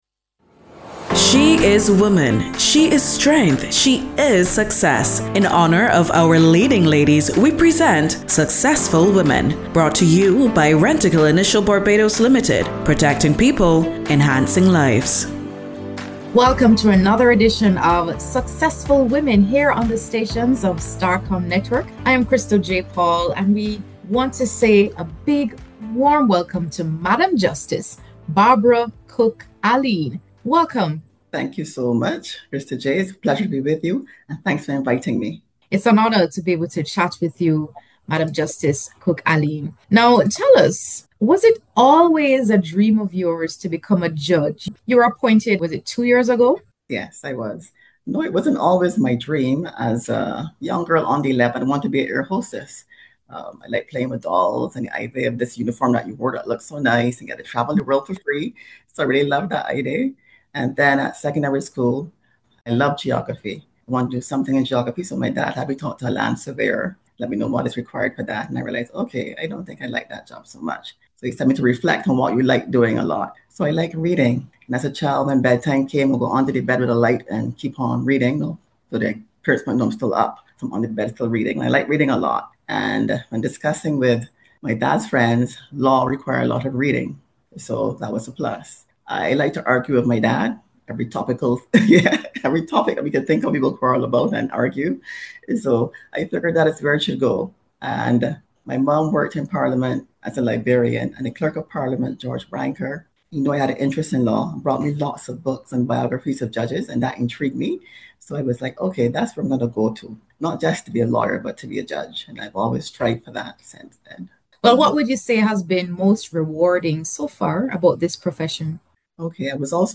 Today on Successful Women, we speak with Madam Justice Barbara Cooke-Alleyne, Barbados High Court Judge.
A conversation on leadership, service to young women, and the role of justice in shaping a fair and accountable society.